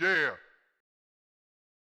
SouthSide Chant (23).wav